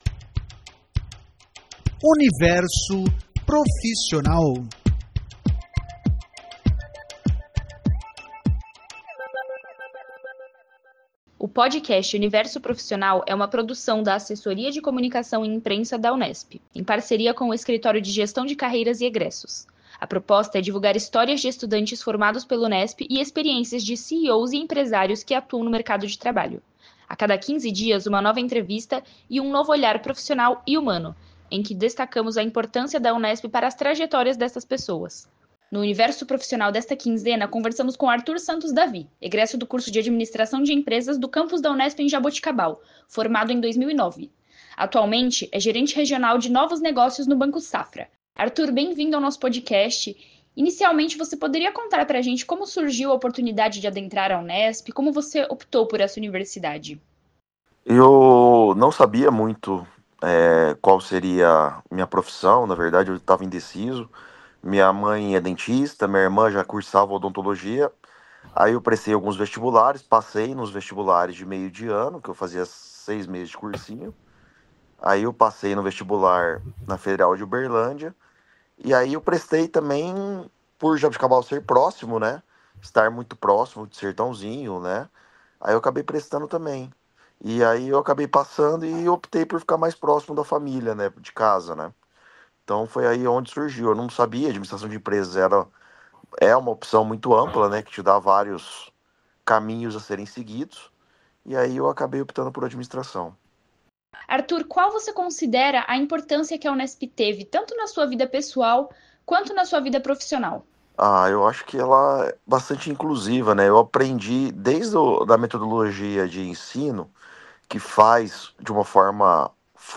A proposta é divulgar histórias de estudantes formados pela Unesp e experiências de CEOs e empresários que atuam no mercado de trabalho. A cada quinze dias, uma nova entrevista e um novo olhar profissional e humano, em que destacamos a importância da Unesp para as trajetórias destas pessoas.